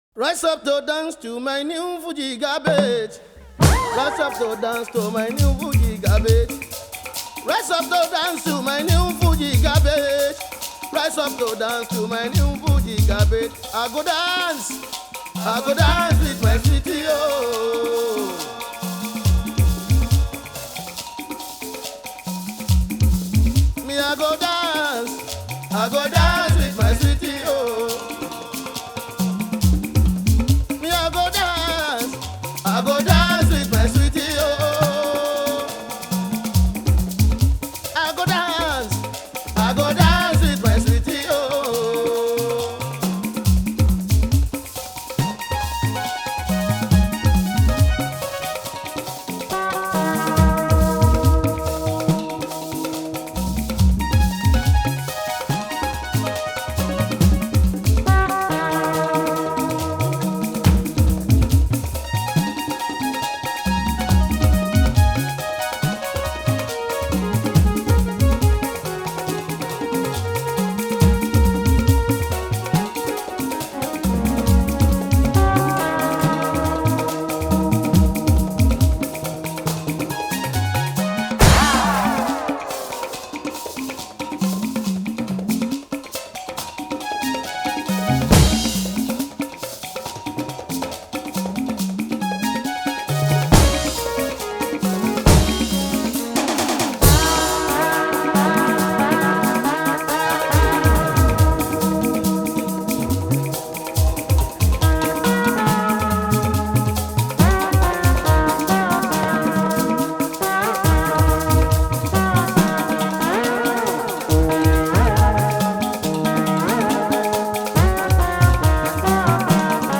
Yoruba Fuji song